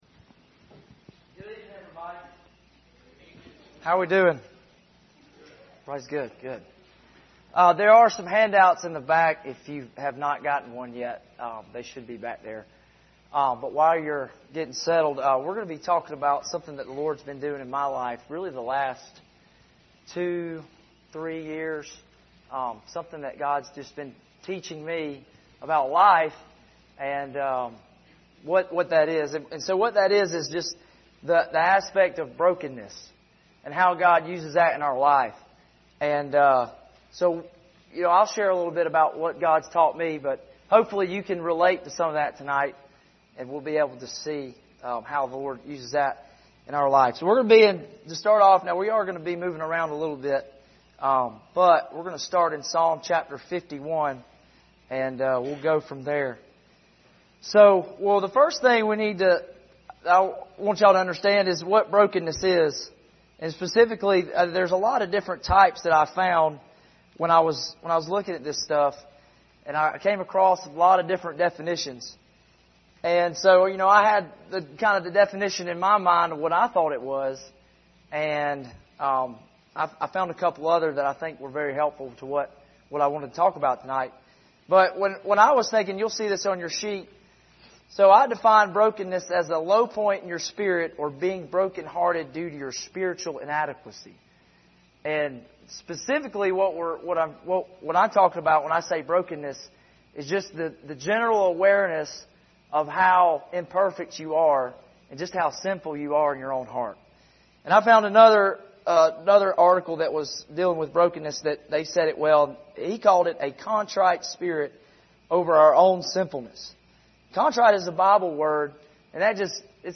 Passage: Psalm 51 Service Type: Wednesday Evening View this video on Facebook « Building Projects Emotionally Healthy Discipleship